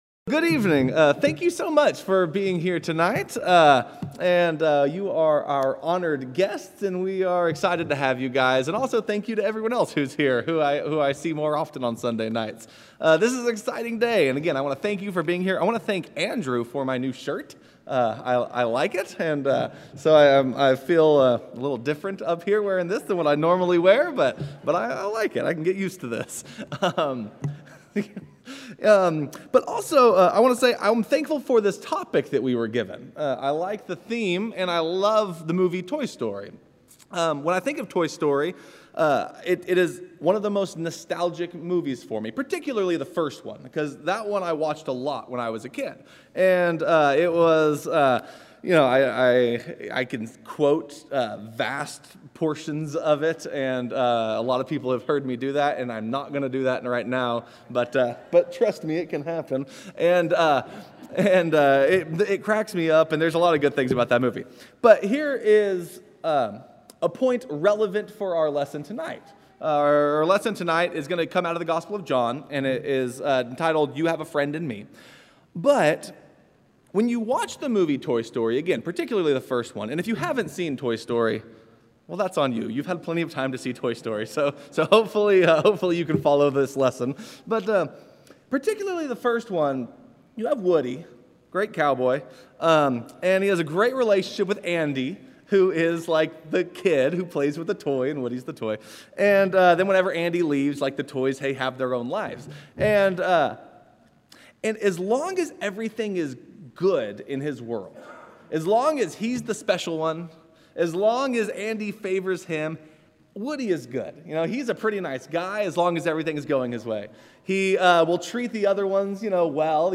Weekly sermons from Maryville Church of Christ in Maryville, Tennessee.